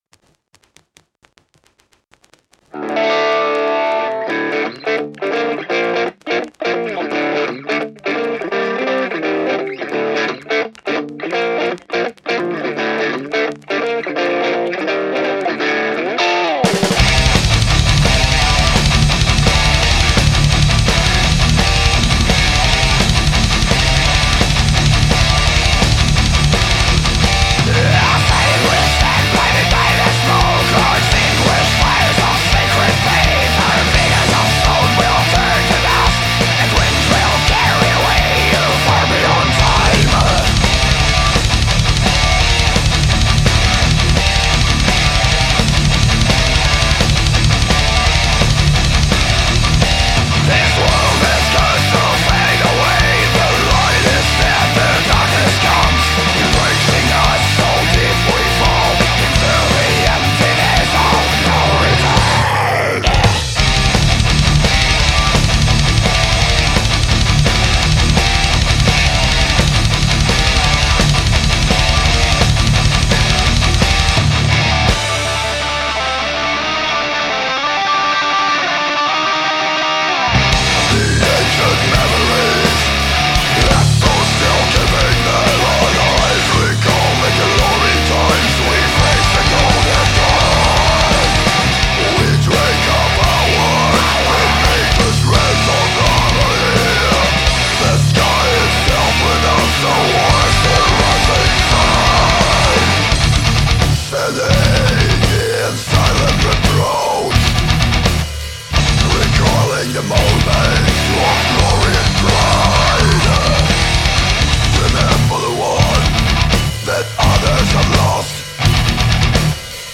Студыя Ministry Of Sun